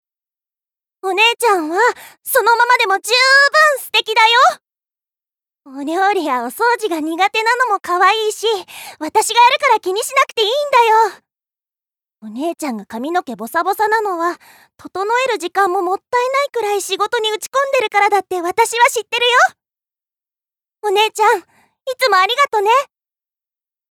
ボイスサンプル
セリフ１